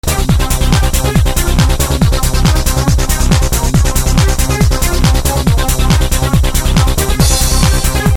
It's psy. Somewhat old school sounding stuff.